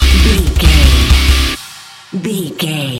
Aeolian/Minor
D
drums
electric guitar
bass guitar
hard rock
aggressive
energetic
intense
nu metal
alternative metal